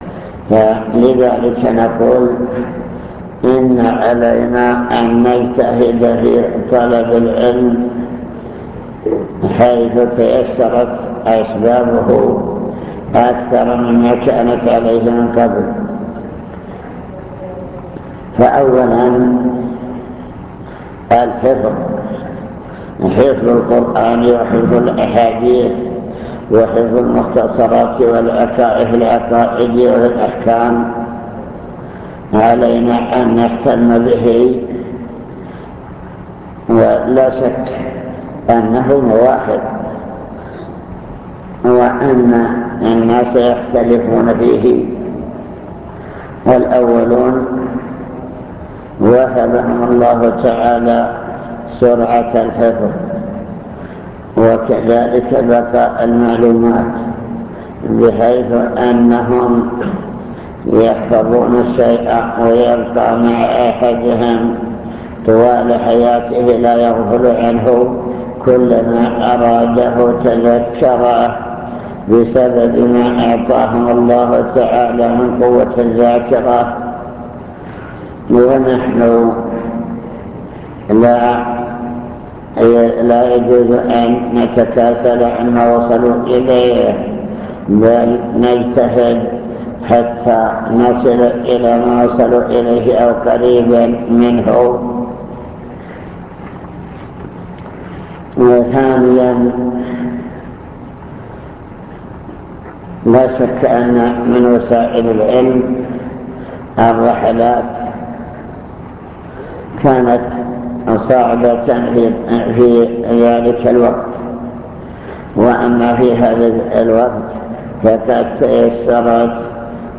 المكتبة الصوتية  تسجيلات - لقاءات  كلمة حول طلب العلم